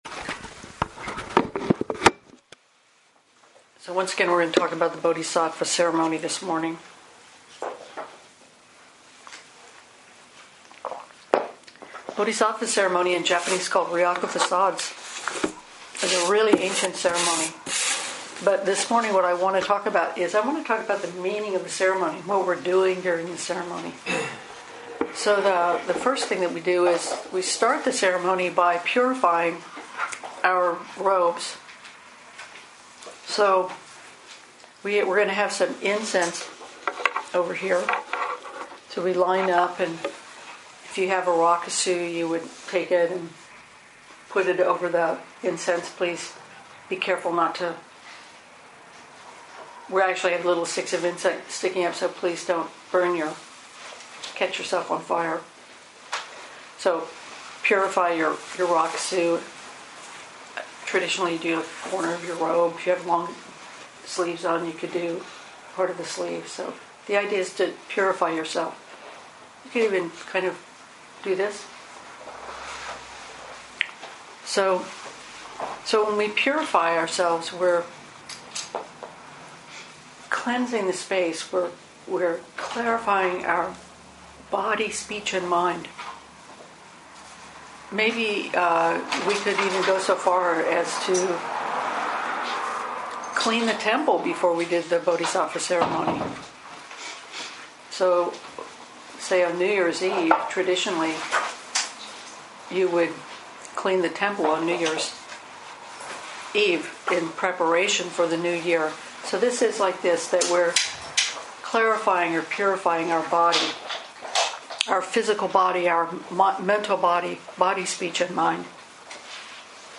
2016 in Dharma Talks